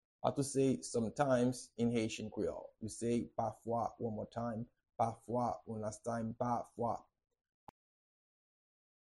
Pronunciation and Transcript:
How-to-say-Sometimes-in-Haitian-Creole-Pafwa-pronunciation.mp3